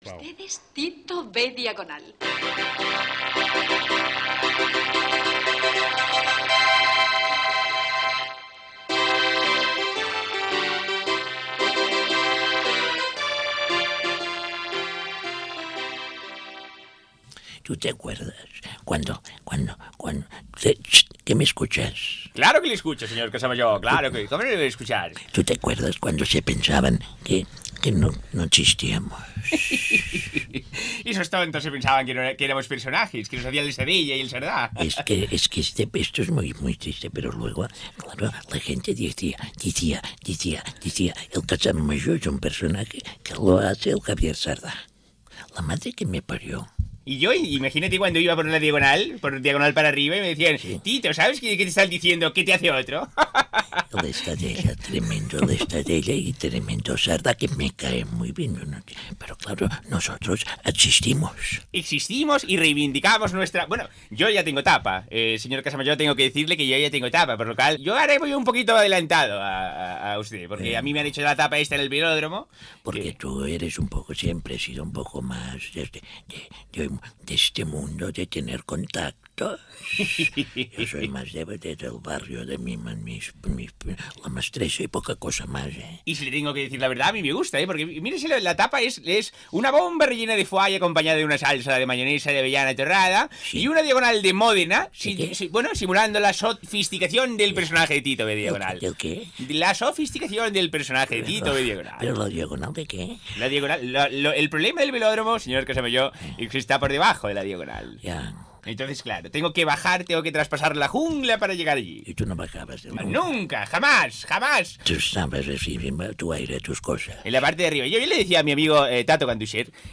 Conversa entre els personatges del Senyor Casamajor i Tito B. Diagonal.
Enregistrament fet el dia de la inauguració de l'exposició "Hasta aquí puedo leer" dedicada a la vida i obra de Jordi Estadella, organitzada pel Col·legi de Periodistes de Catalunya, a la seva seu de Barcelona. Carlos Latre és qui fa de Tito B. Diagonal i dialoga amb el Senyor Casamajor (Xavier Sardà)